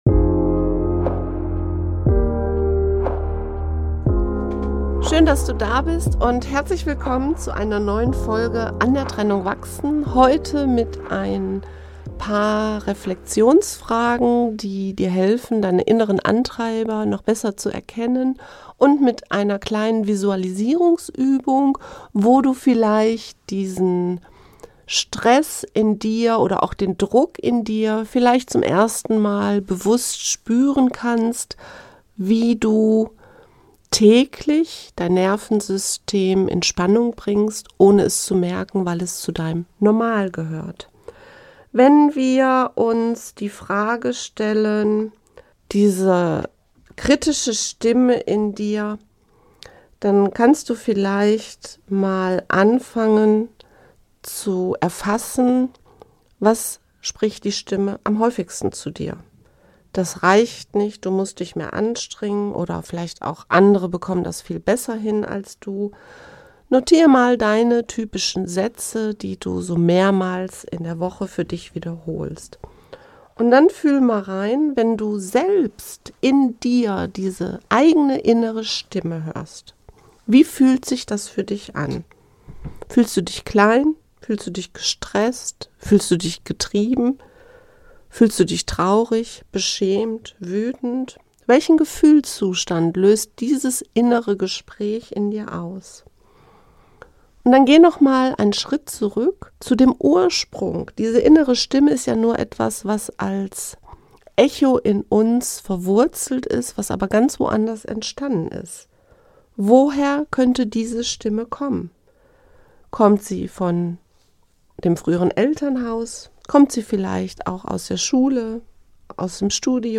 In dieser geführten Meditation lade ich dich ein, deinem inneren Antreiber und deinen Kritiker auf sanfte Weise zu begegnen – nicht um sie zu bekämpfen, sondern um sie zu verstehen.